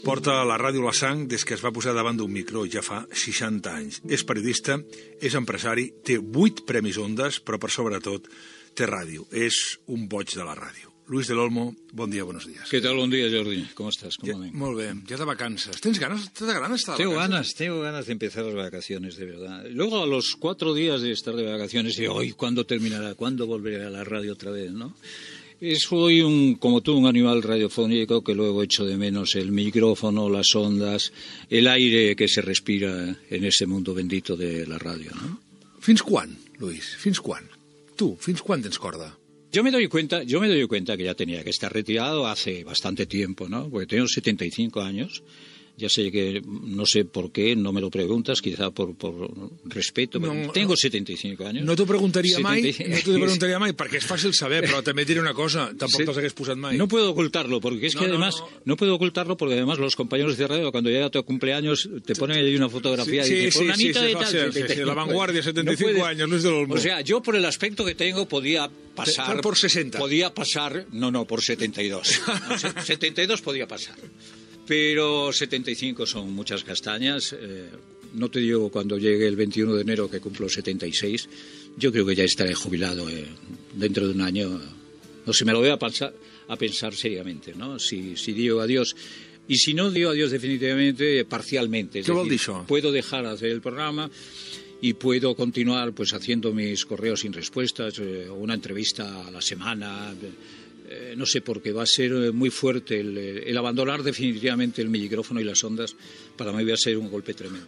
Fragment d'una entrevista a Luis del Olmo.
Info-entreteniment